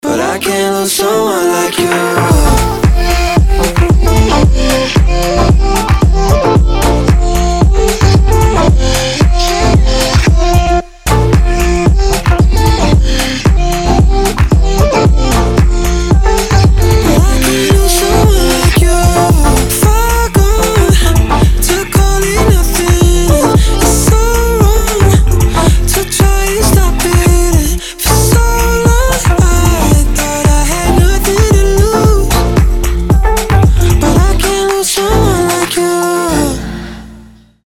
• Качество: 320, Stereo
dance
Electronic
EDM
приятные